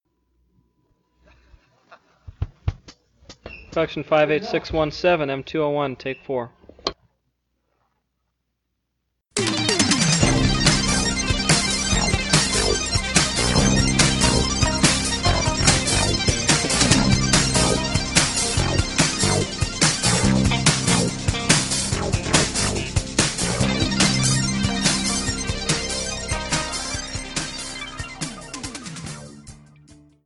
UPC: Soundtrack